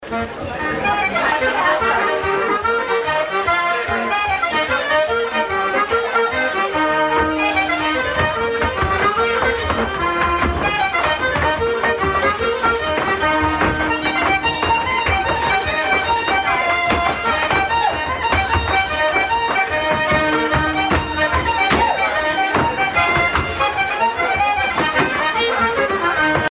set dance (Irlande)
Pièce musicale éditée